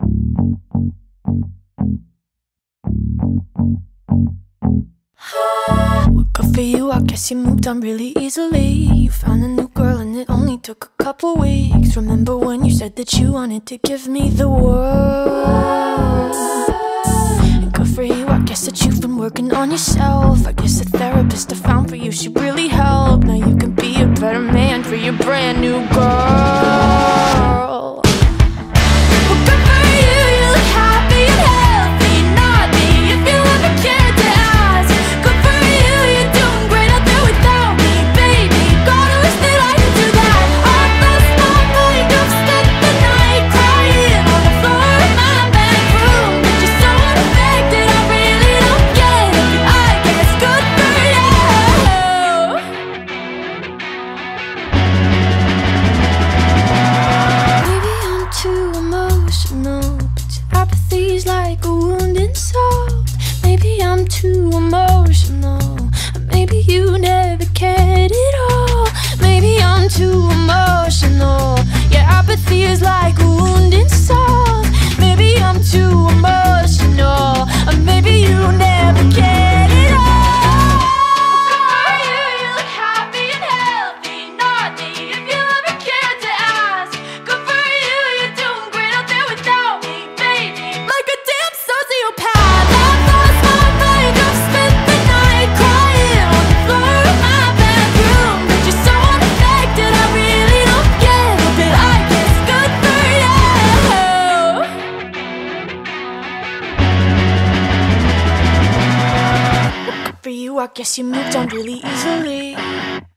BPM163-171
Audio QualityMusic Cut